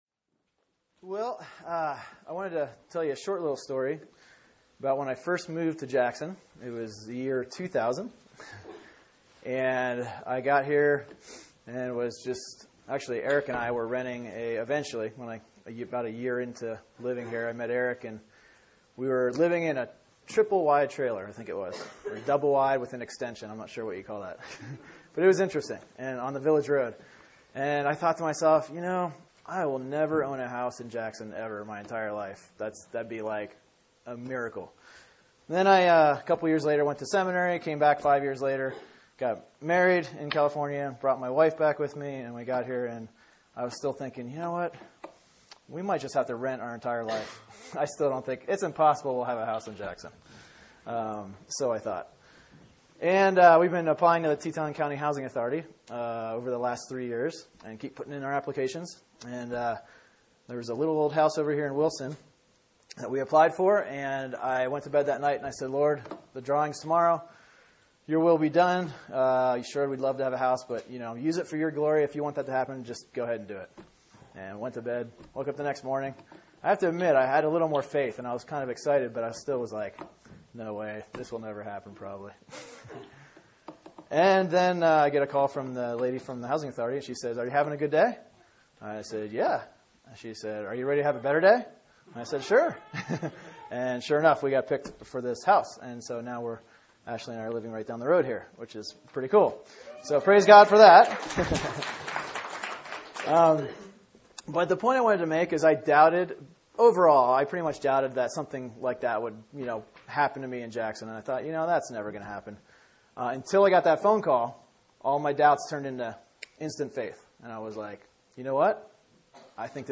Sermon: John 20:19-31 “What Brings You Peace?”